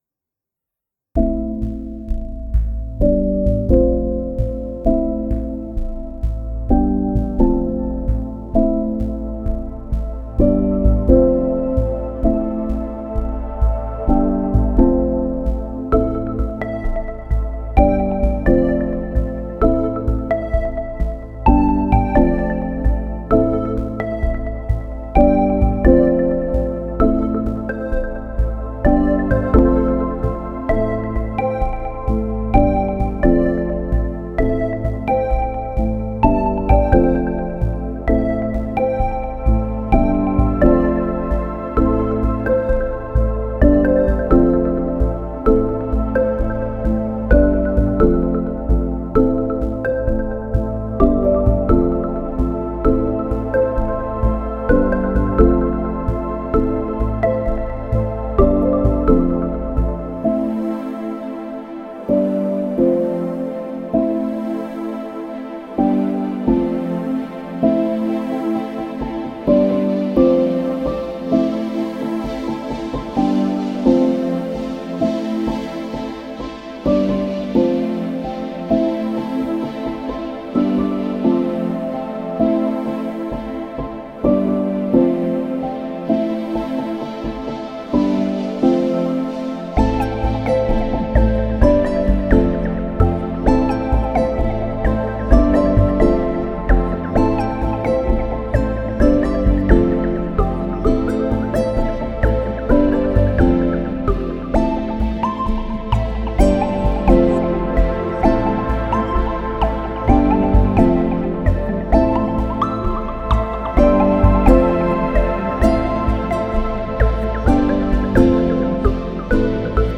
Chillhop track for reality TV and casual.